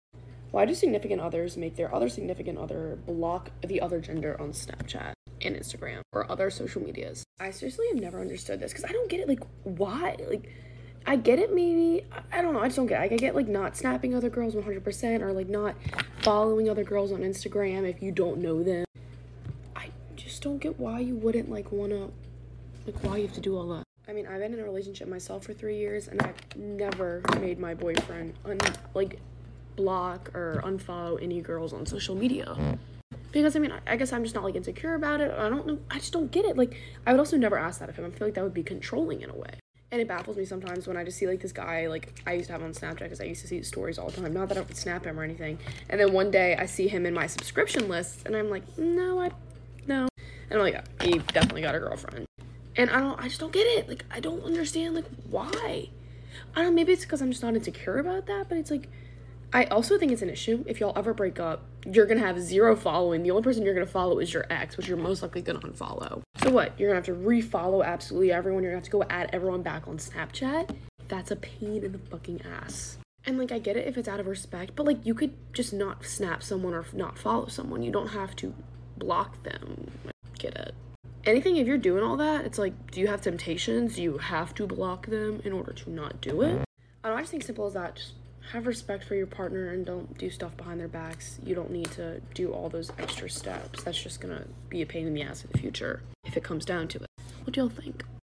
Plz itnore my hand sliding on my desk noise